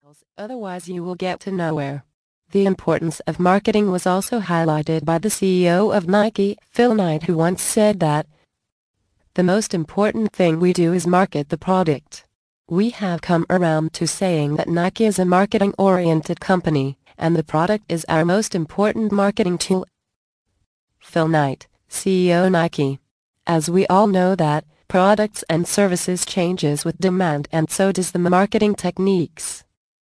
Article Marketing Secrets mp3 audio book + FREE Gift